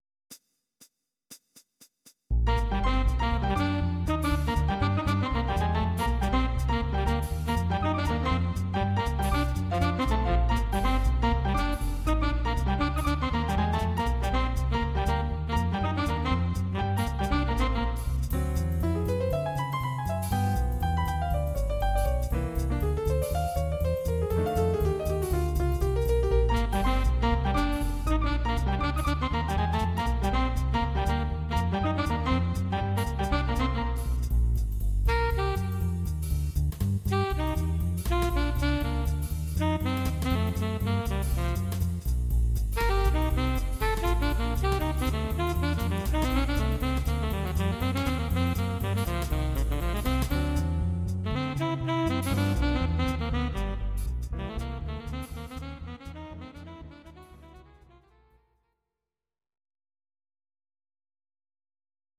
Bb
Audio Recordings based on Midi-files
Jazz/Big Band, Instrumental, 1950s